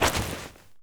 foley_jump_movement_throw_04.wav